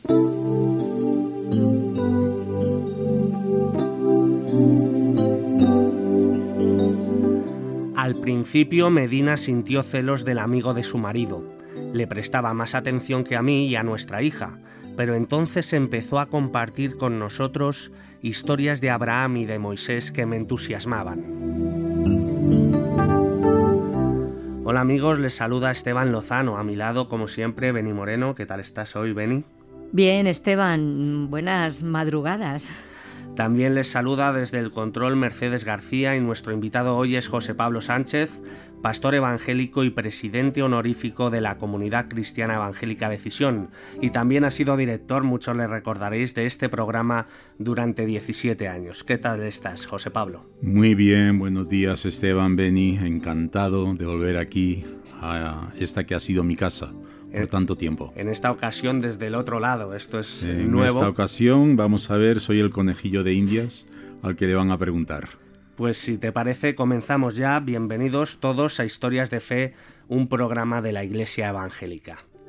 Inici del programa de l'Església Evangèlica.